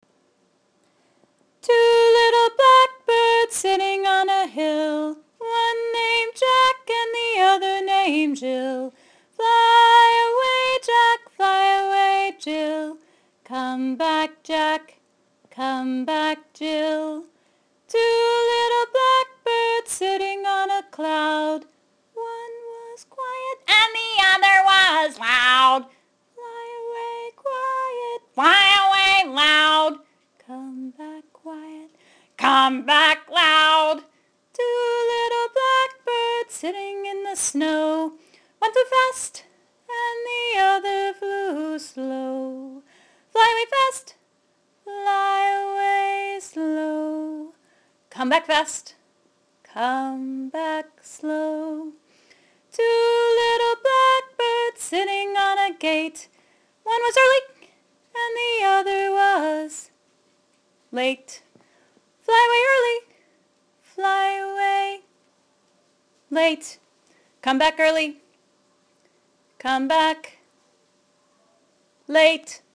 I usually sing it a cappella, so I can do the hand motions.
two-little-blackbirds.mp3